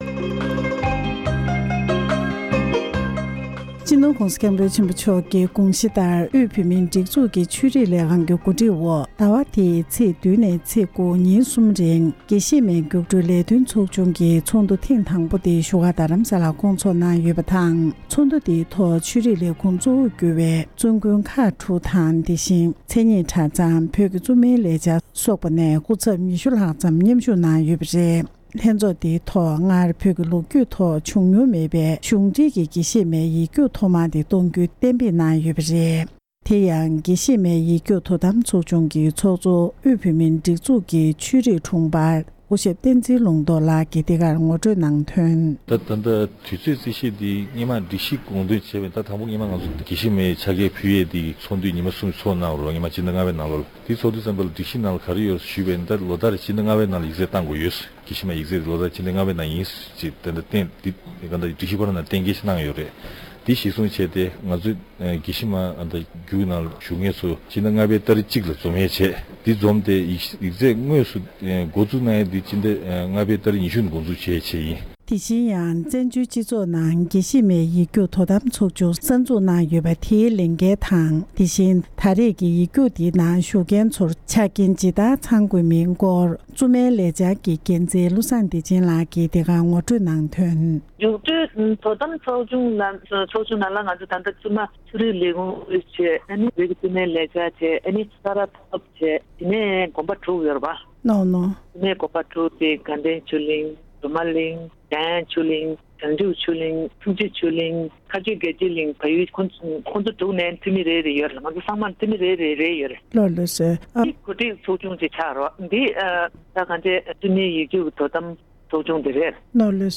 འབྲེལ་ཡོད་མི་སྣར་གནས་འདྲི་ཞུས་ཏེ་གནས་ཚུལ་ཕྱོགས་སྒྲིགས་ཞུས་པ་ཞིག་གསན་རོགས་ཞུ༎